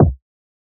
DDWV KICK 4.wav